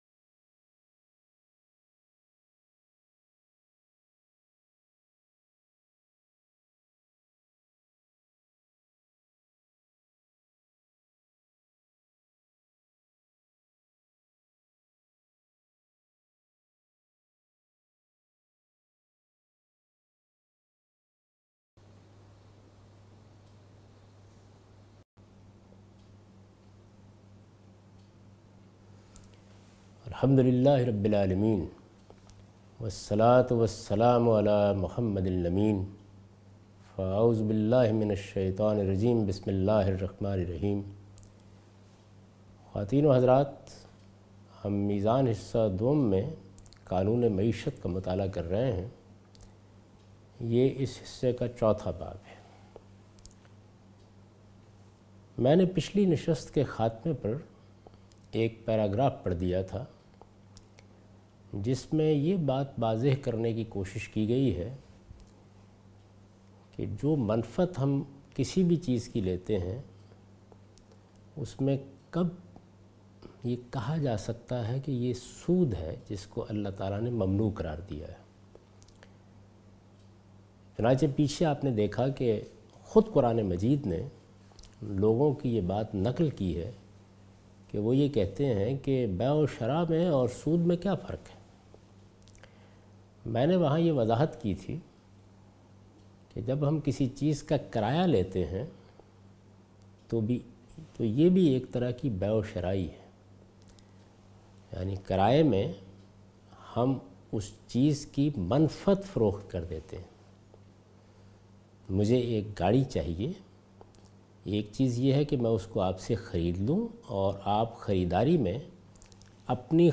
Javed Ahmad Ghamidi teaching from his book Meezan. In this lecture he teaches from the chapter "Qanoon e Maeshat".